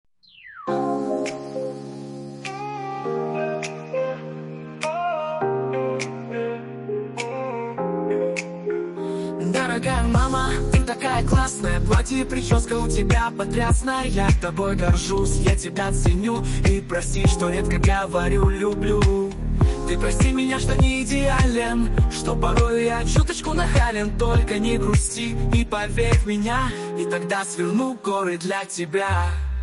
Отрывок примера исполнения: